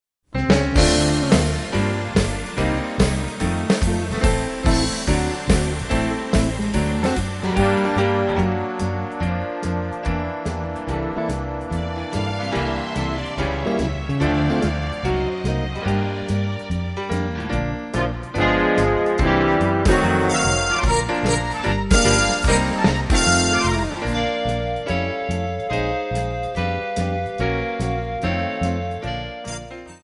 Backing track files: Jazz/Big Band (222)